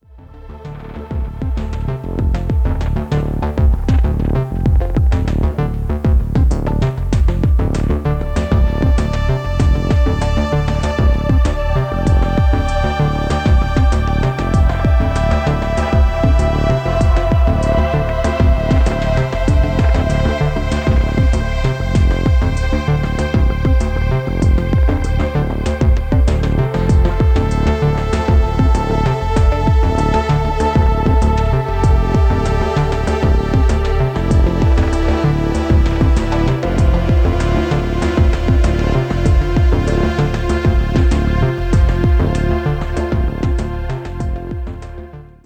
Below is a test recording made by the KX-380 with the AutoTune function used for optimal recording:
Yamaha-KX-380-Test-Recording.mp3